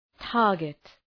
Προφορά
{‘tɑ:rgıt}